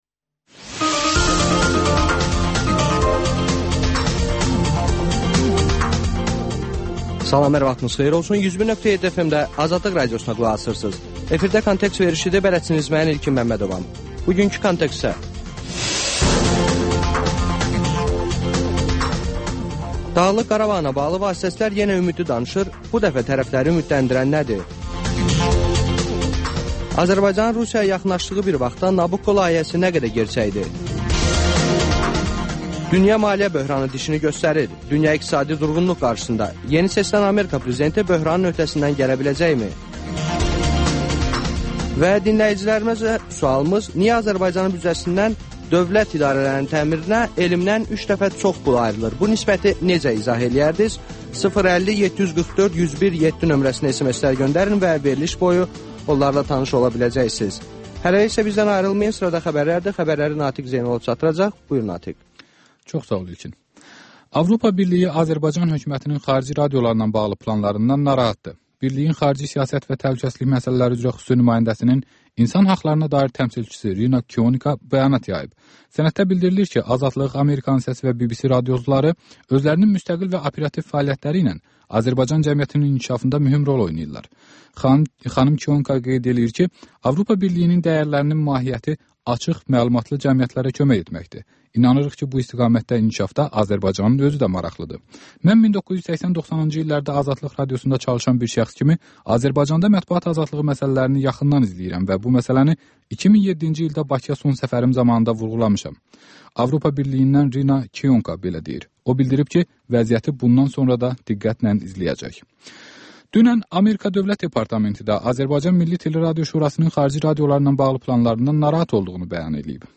Xəbərlər, müsahibələr, hadisələrin müzakirəsi, təhlillər, sonda isə HƏMYERLİ rubrikası: Xaricdə yaşayan azərbaycanlıların həyatı